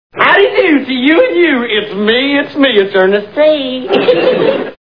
The Andy Griffith TV Show Sound Bites